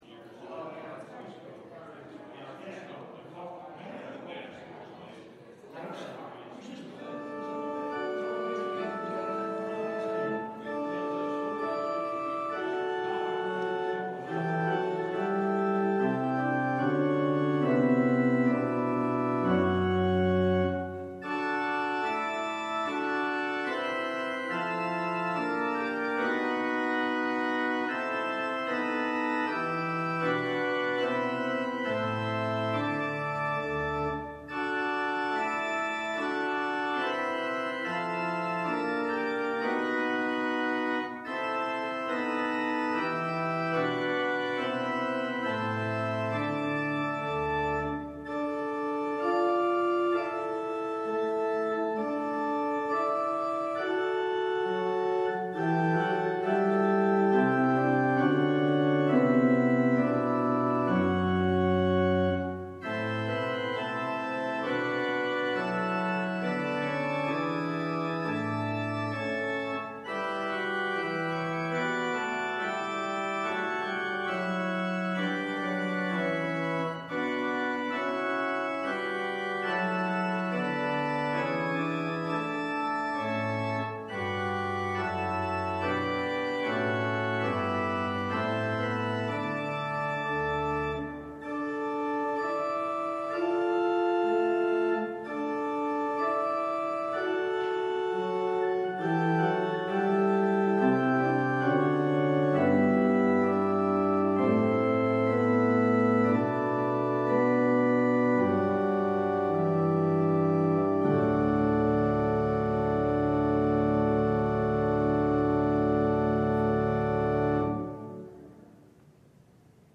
LIVE Evening Worship Service - Jesus Repents
Congregational singing—of both traditional hymns and newer ones—is typically supported by our pipe organ.